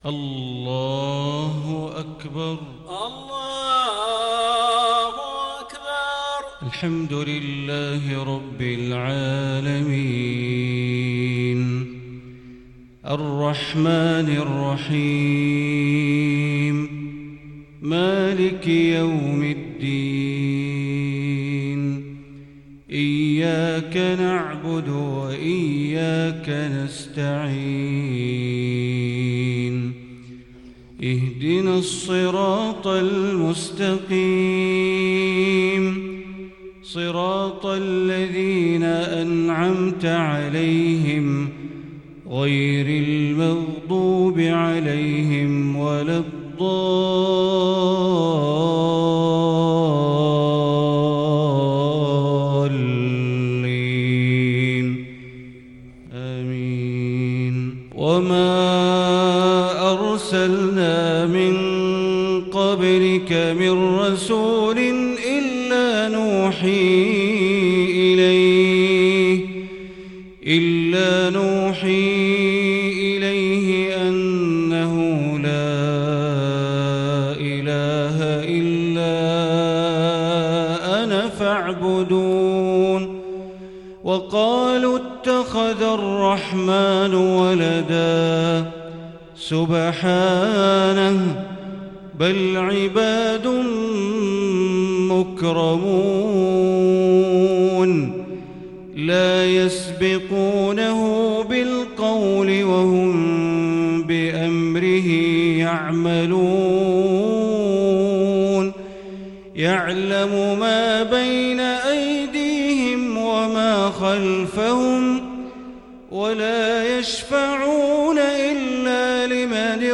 صلاة المغرب للشيخ بندر بليلة 18 شعبان 1441 هـ
تِلَاوَات الْحَرَمَيْن .